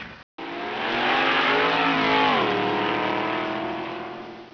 Un son (wave) d'une formule 1
doppler.wav